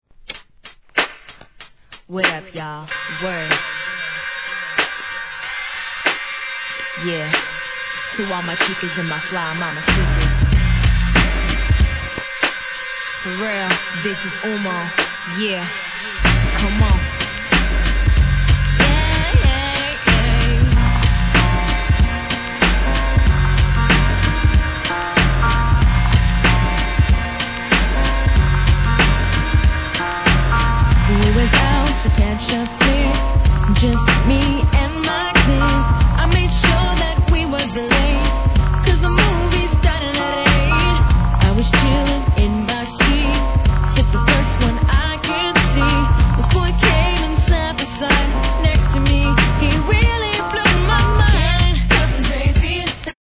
HIP HOP/R&B
カナダ発R&B、4 TRACKS EP!!